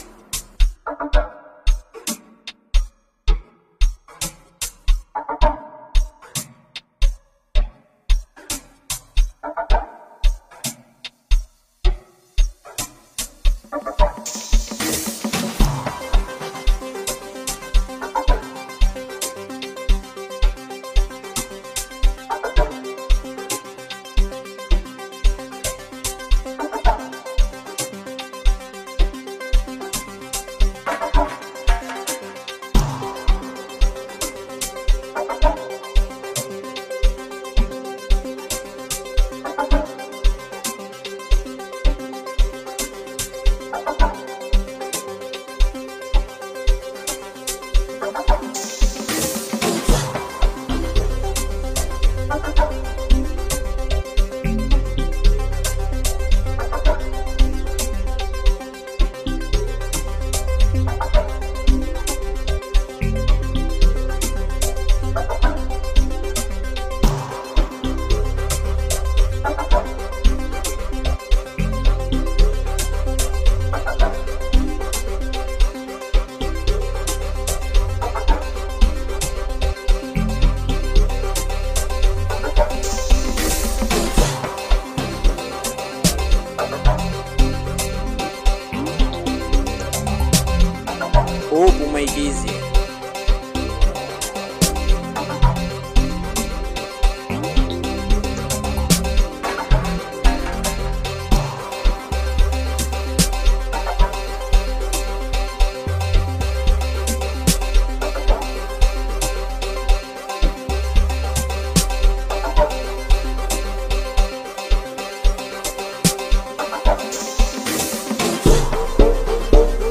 Genre: Amapiano, Afro-house.